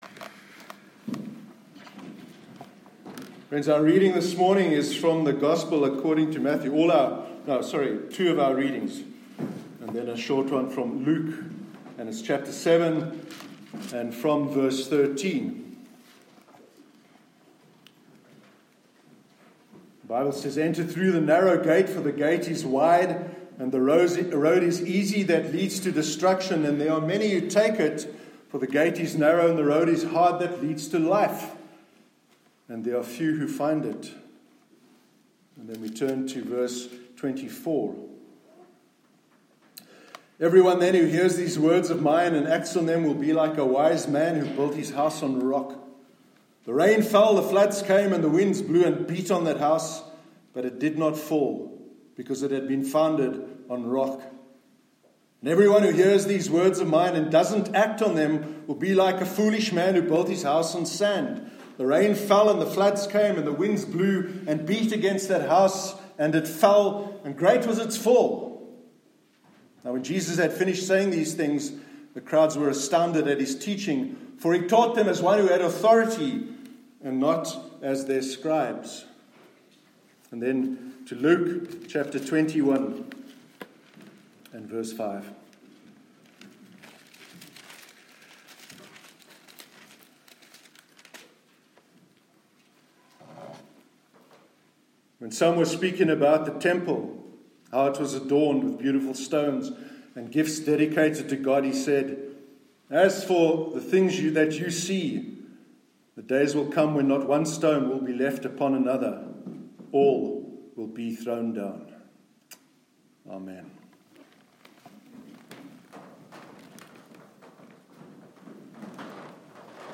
Sermon on Building Our Lives on Rock- 28th October 2018